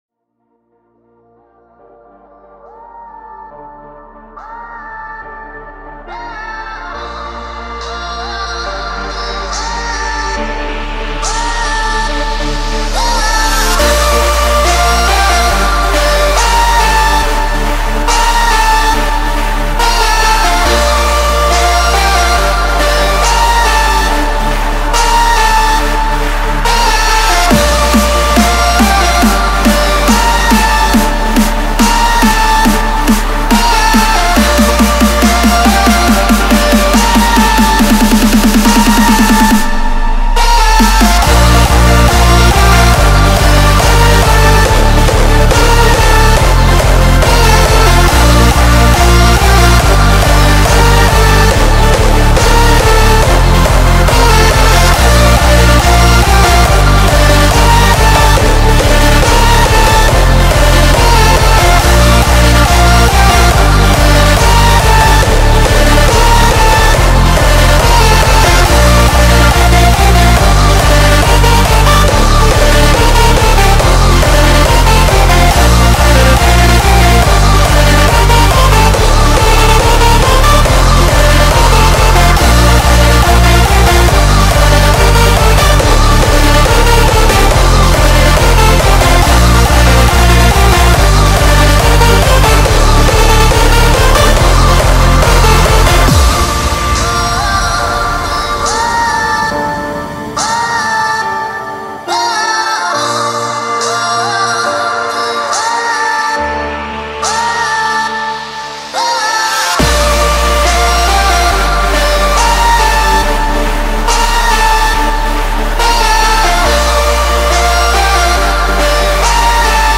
Index of: /data/localtracks/Hardstyle/